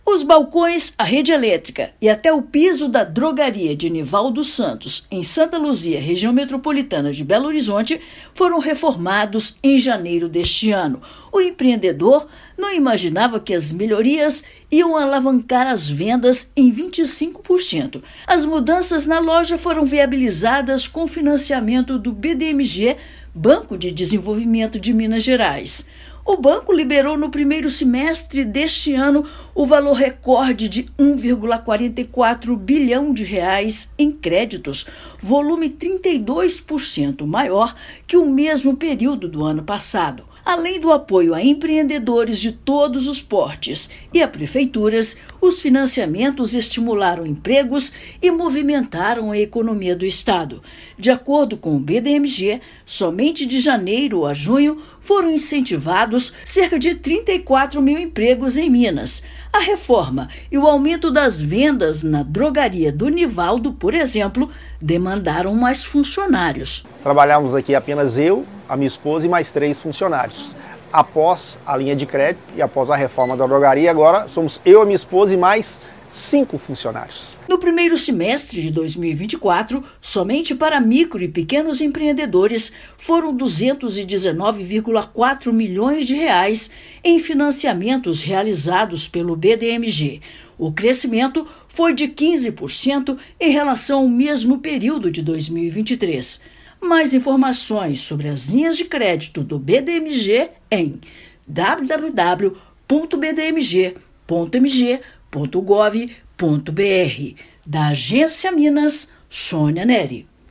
No primeiro semestre do ano, Banco de Desenvolvimento de Minas Gerais (BDMG) liberou R$ 1,44 bilhão, estimulando 34 mil empregos no estado. Ouça matéria de rádio.